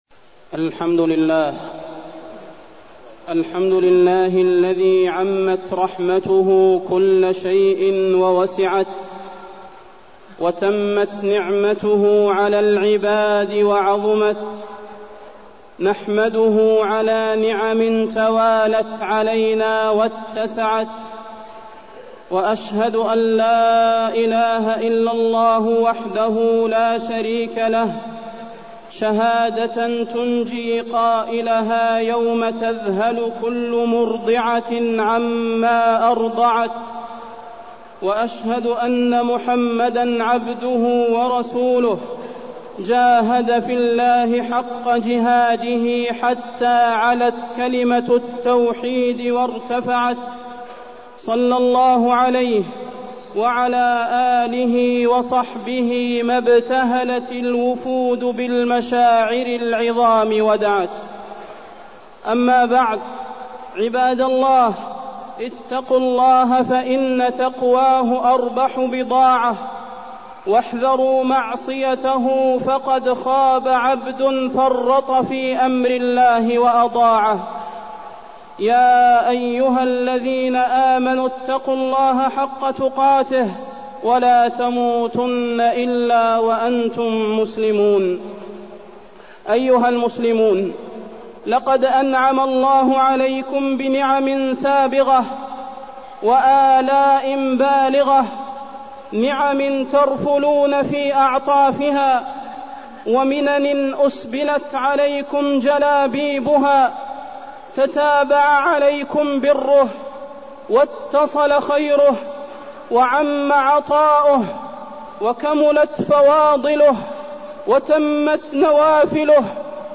خطبة تحقيق العبودية لله عز وجل وفيها: عبادة الله أوجب الواجبات وكيفية تحقيقها، وأيام التشريق وفضلها وأنها أيام أكل وشرب وذكر لله
تاريخ النشر ١١ ذو الحجة ١٤٢٠ المكان: المسجد النبوي الشيخ: فضيلة الشيخ د. صلاح بن محمد البدير فضيلة الشيخ د. صلاح بن محمد البدير تحقيق العبودية لله عز وجل